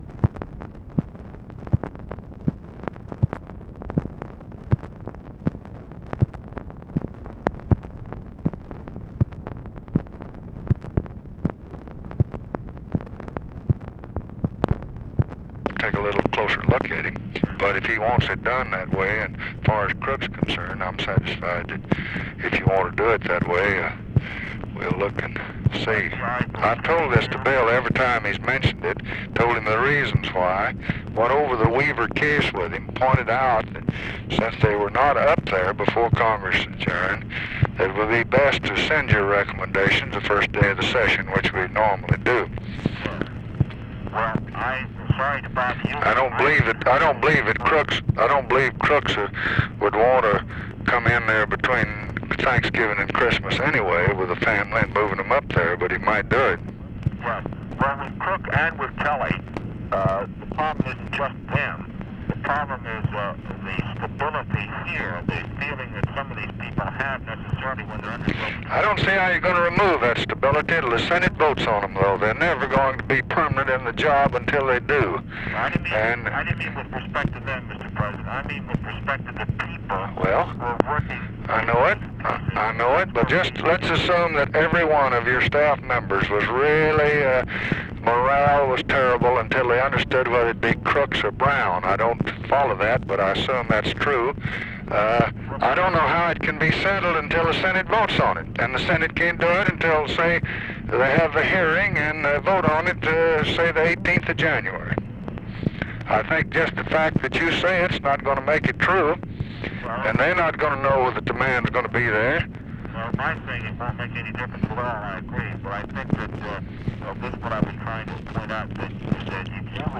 Conversation with SARGENT SHRIVER, November 22, 1966
Secret White House Tapes